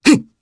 Fluss-Vox_Attack1_jp.wav